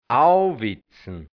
Mundart-Wörter | Mundart-Lexikon | hianzisch-deutsch | Redewendungen | Dialekt | Burgenland | Mundart-Suche: A Seite: 17